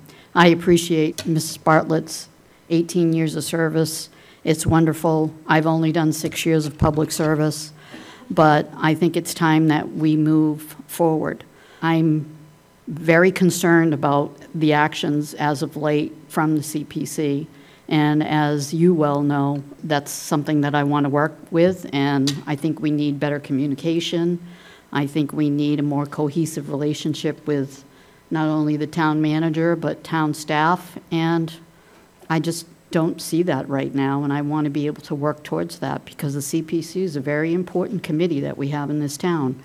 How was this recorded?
It was a packed meeting at Town Hall on June 20th, with a seat on the Community Preservation Committee drawing interest.